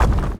A_SFX_Earth_Moving_01.wav